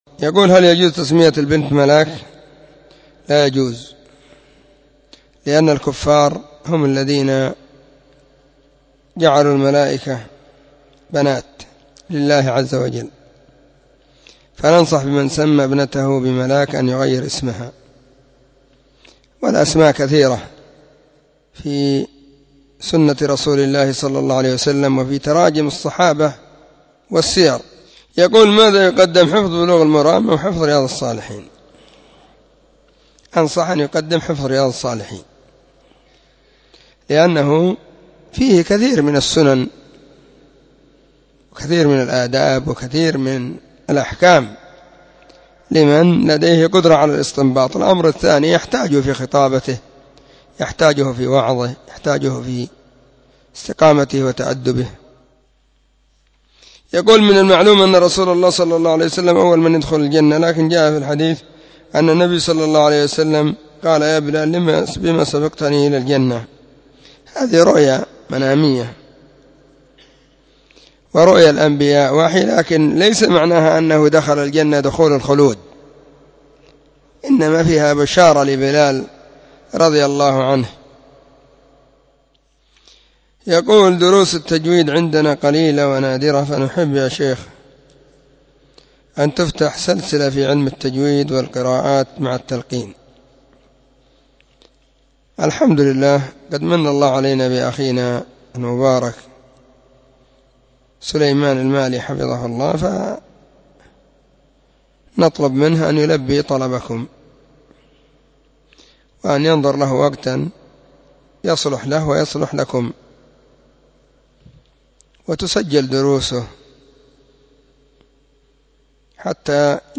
🔹 سلسلة الفتاوى الصوتية 🔸الاثنين 8 /محرم/ 1443 هجرية.